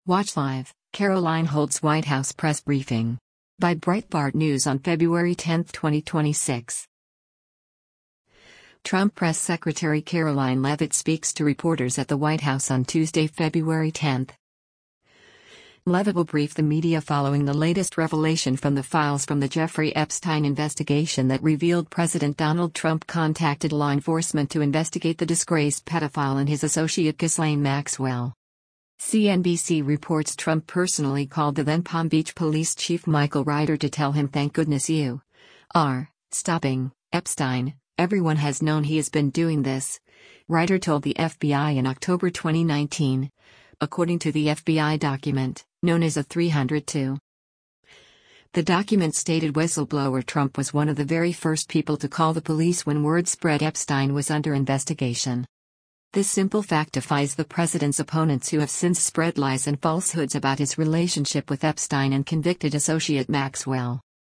Trump Press Secretary Karoline Leavitt speaks to reporters at the White House on Tuesday, February 10.